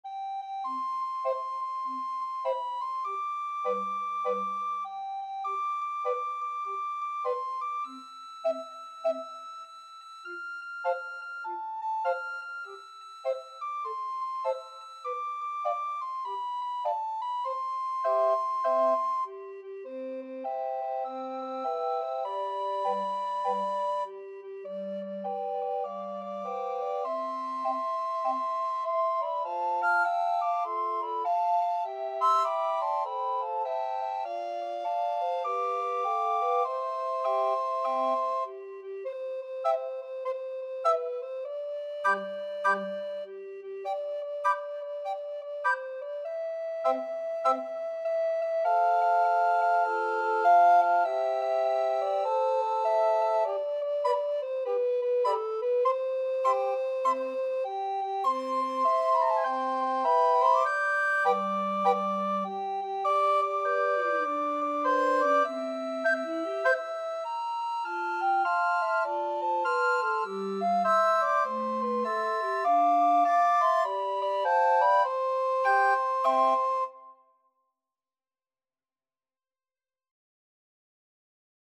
Free Sheet music for Recorder Ensemble
Soprano Recorder 1Soprano Recorder 2Alto Recorder 1Alto Recorder 2Tenor RecorderBass Recorder
C major (Sounding Pitch) (View more C major Music for Recorder Ensemble )
4/4 (View more 4/4 Music)
With a swing!
Recorder Ensemble  (View more Easy Recorder Ensemble Music)
Traditional (View more Traditional Recorder Ensemble Music)
happy_and_you_know_it_RECE.mp3